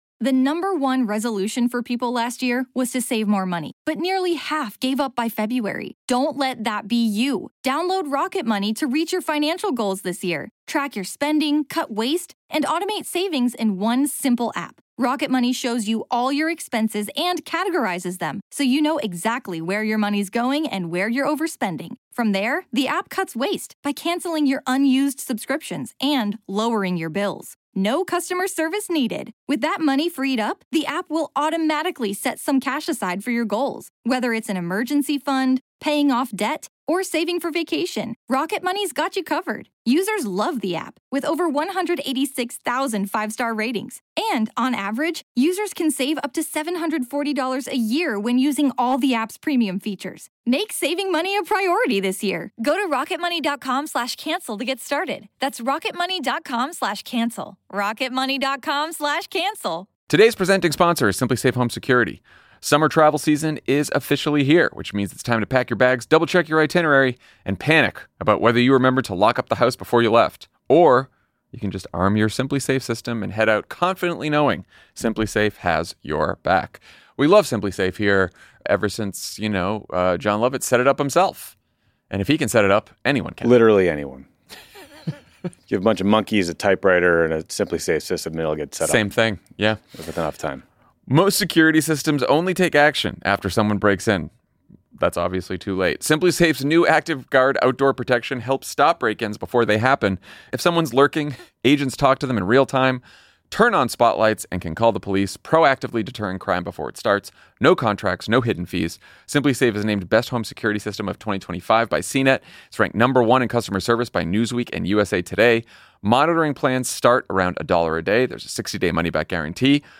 Then, Tommy asks Governor Gavin Newsom about Trump's threats to arrest him and how he's pushing back against the President's authoritarian playbook.